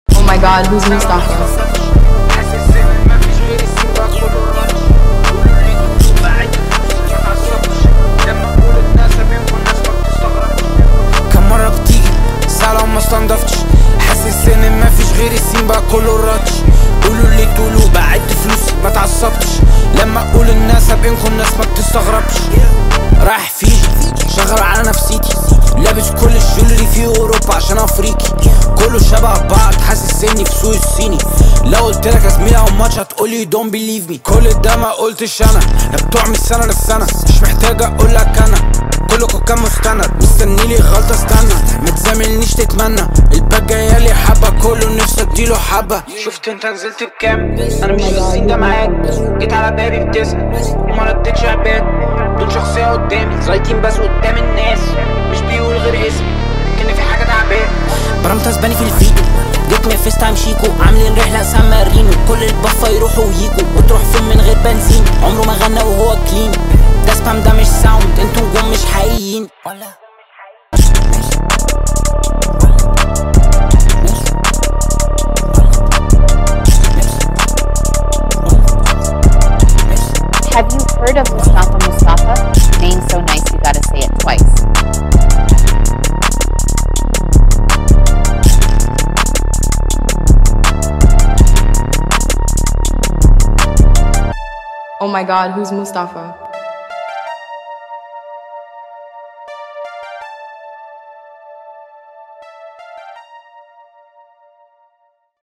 وأحد أبرز الأسماء في عالم الراب والتراب!